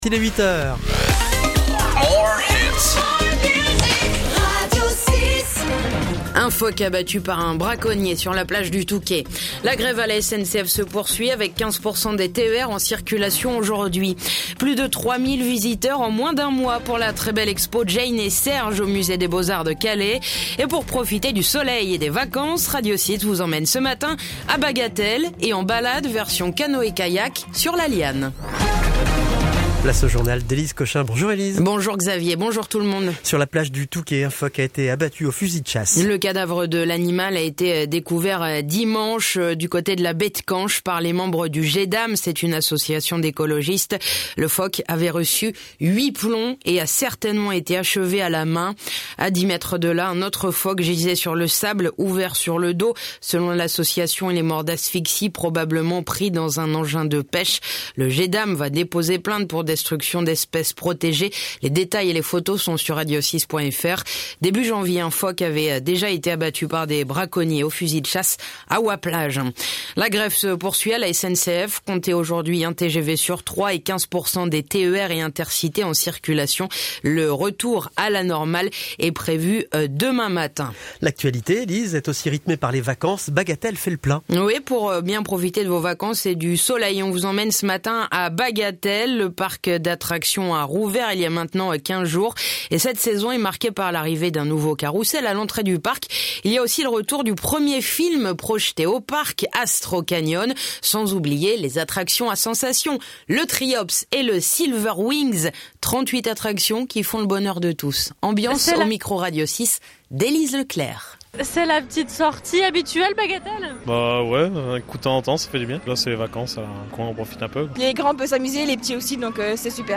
L'essentiel de l'actu de la Côte d'Opale et les titres de l'actu nationale et internationale dans un journal de 6 minutes ! (édition de 8h)